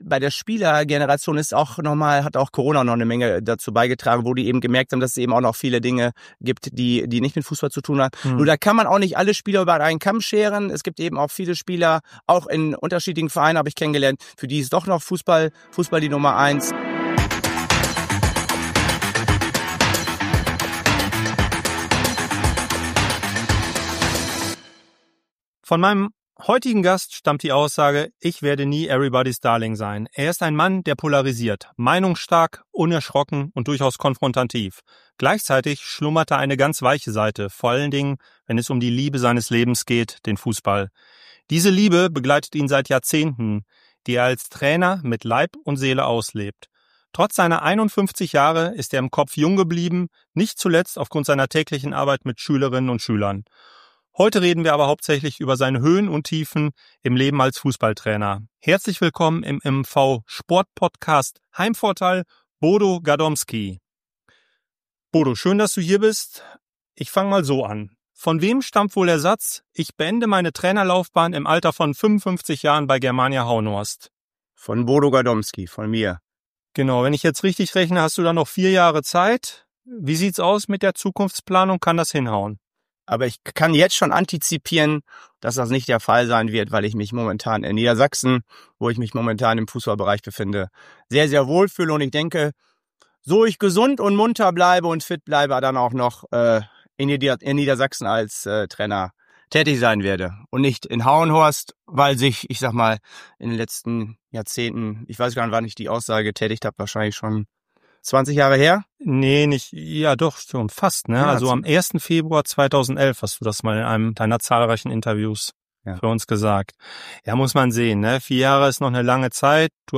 Ein Interview mit einem Fußball-Verliebten mit Ecken und Kanten.